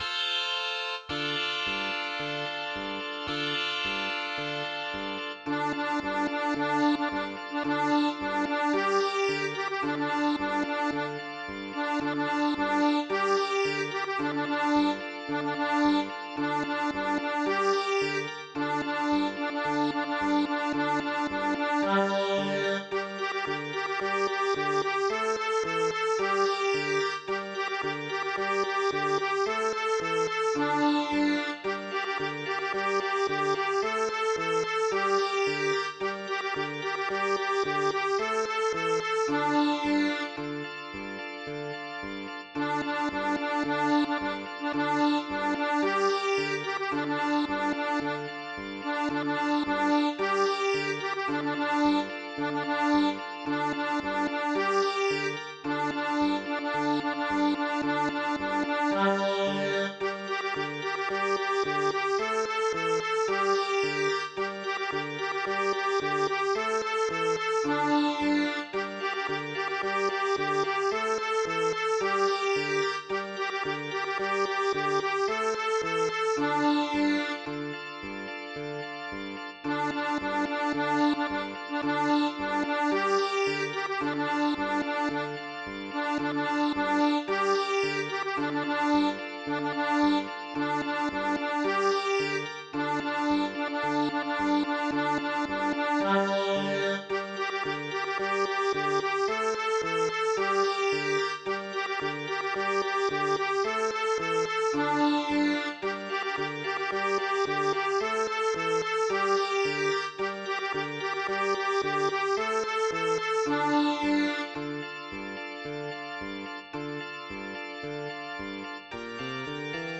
Attempt number two was converting the midi file to mp3.
Six total hours and a full intervening day later, I had used two different programs to generate the audio data to pipe into ffmpeg, and compared the result of each with different configured soundfonts before settling on my creation: